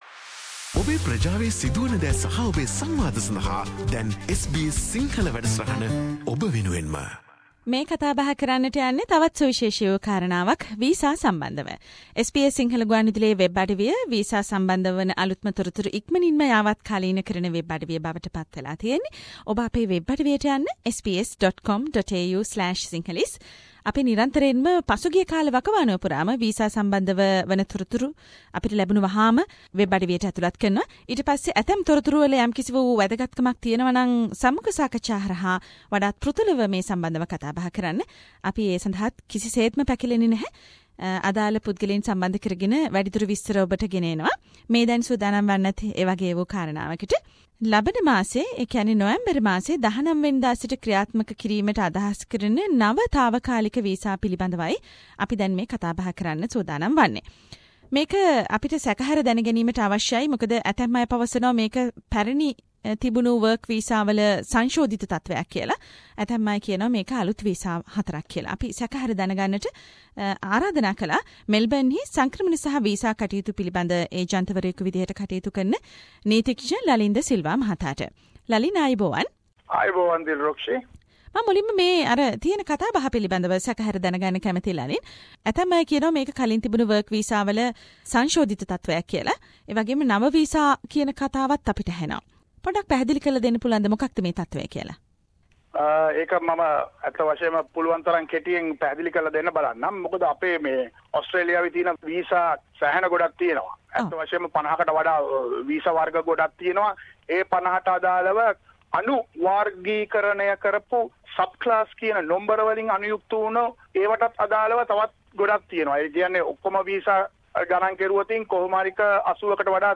එසේම නව විසා රෙගුලාසි මොනවාද. SBS සිංහල වැඩසටහන ඒ පිළිබඳව ඔබ වෙත ගෙන එන සාකච්චාවක්......